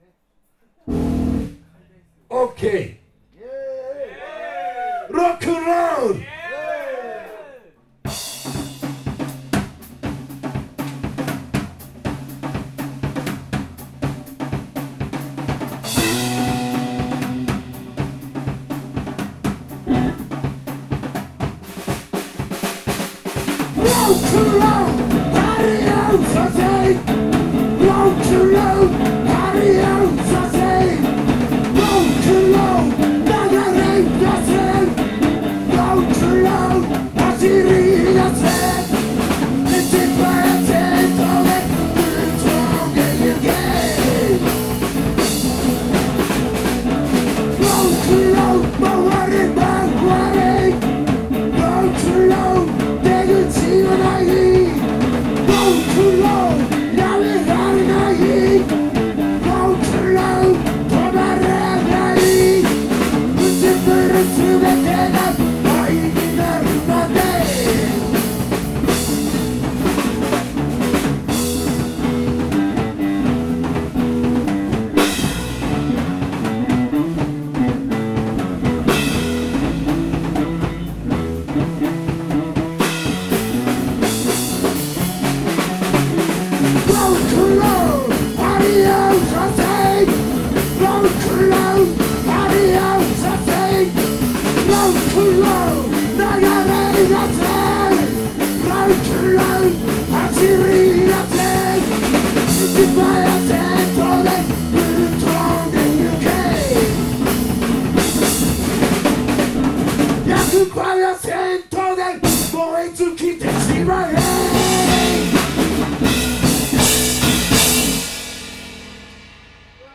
個人的には、ドラムとベースの2ピースで極められる限界領域まで行った感があります。
2人編成でのライブ音源の代表的なもの。